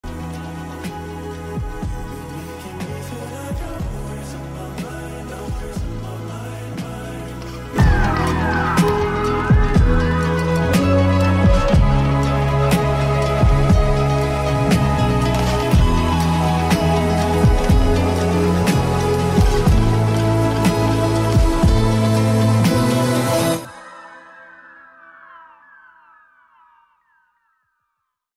VIBE/DARK/AESTHETIC AUDIO